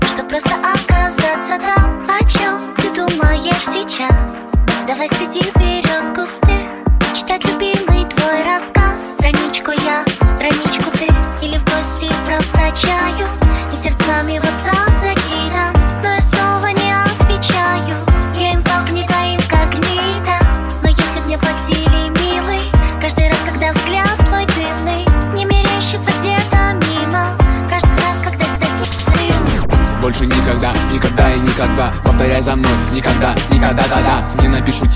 Жанр: Хип-Хоп / Рэп / Русский рэп / Русские
Hip-Hop, Rap